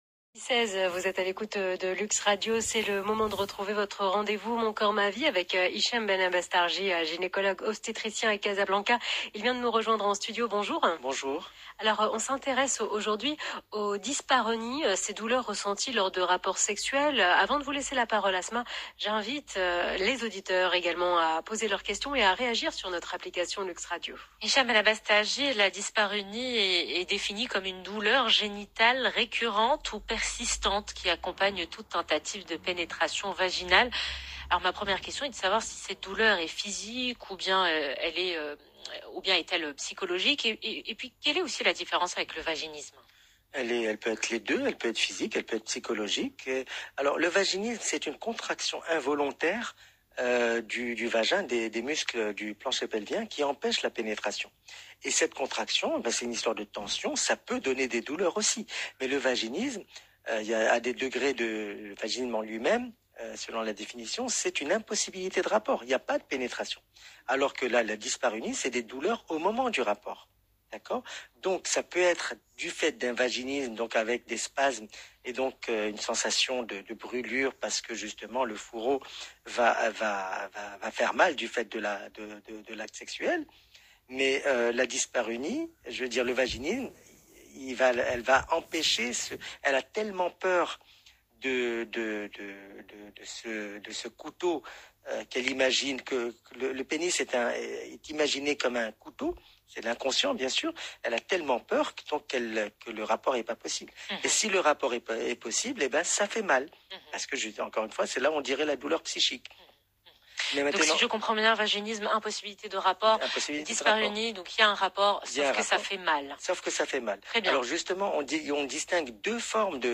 Plus d’explications dans cette interview de l’Heure Essentielle sur LUXE RADIO du 15 juin 2021